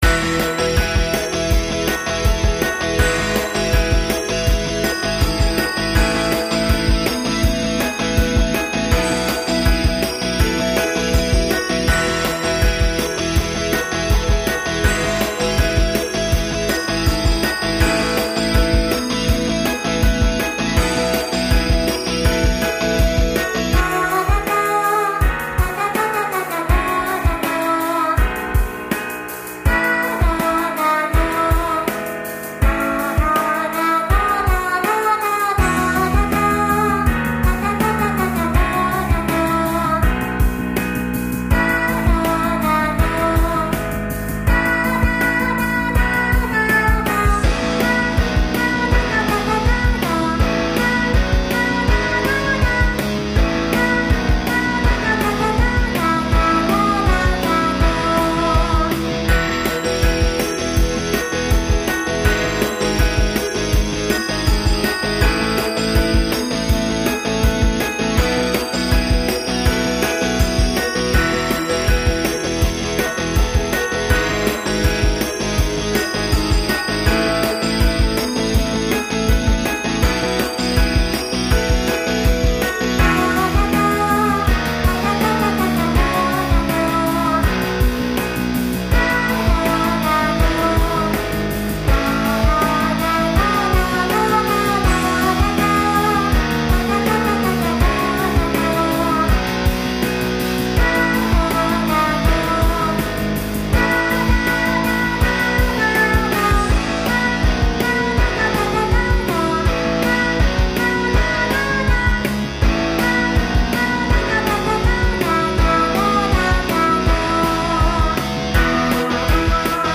イントロのキーボードリフからできてった。
全部ソフトシンセで作れた。
なんとか、歌ものっぽい曲にはなったな。。。
Ａメロのカノン進行とかもありきたりだけど、やっぱいいもんだな。